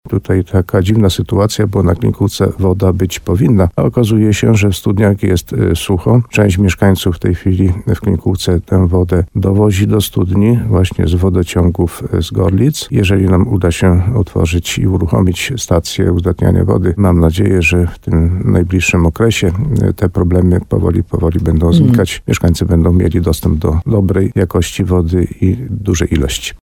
– To oznacza, że na przełomie maja i czerwca mieszkańcy tej części powiatu gorlickiego będą korzystać z wody z Zalewu Klimkówka – mówił w programie Słowo za Słowo na antenie radia RDN Nowy Sącz Karol Górski, wójt gminy Ropa.